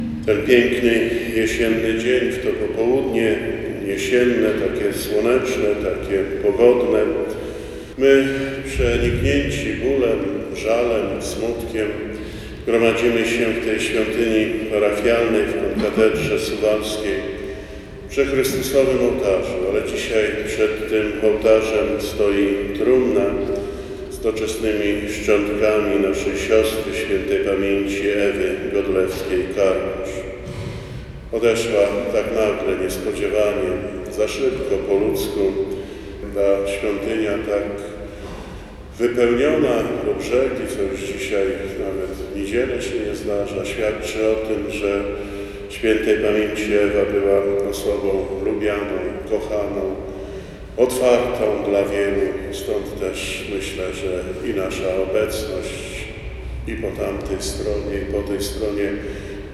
Wcześniej, w konkatedrze św. Aleksandra, odbyła się msza święta pogrzebowa.
Pogrzeb.mp3